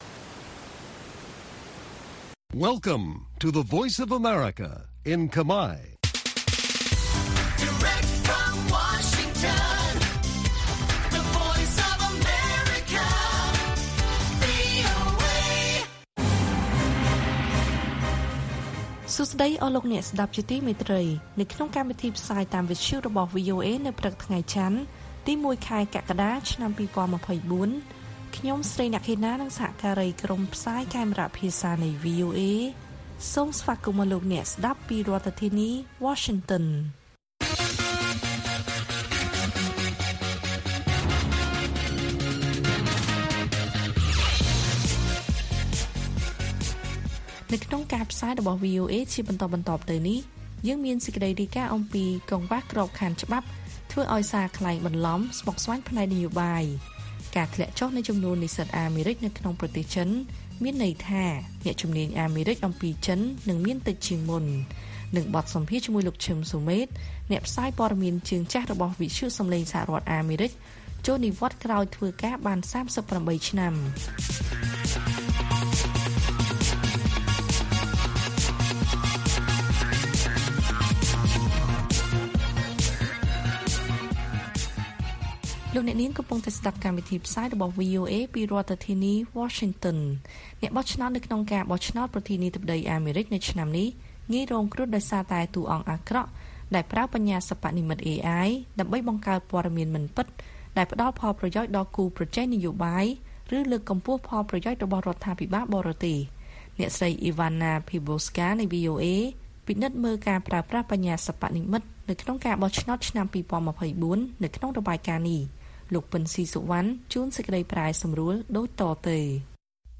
ព័ត៌មានពេលព្រឹក